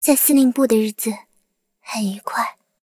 追猎者被击毁语音.OGG